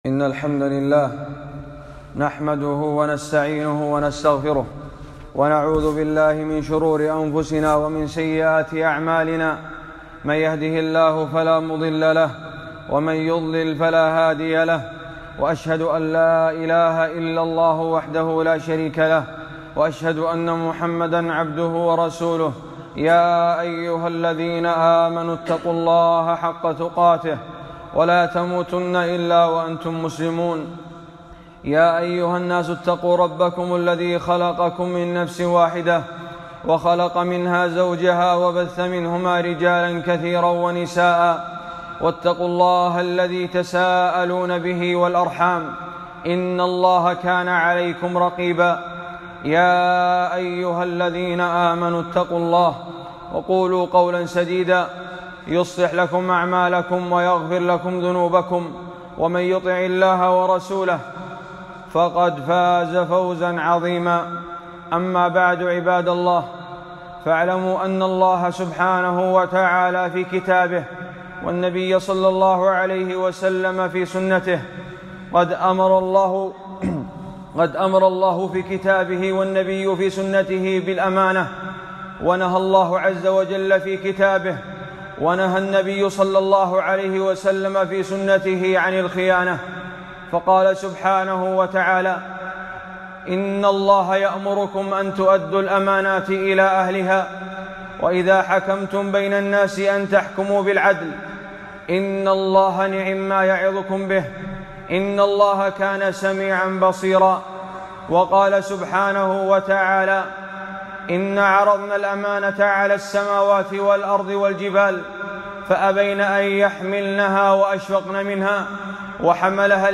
خطبة - ومن يغلل يأتِ بما غل يوم القيامة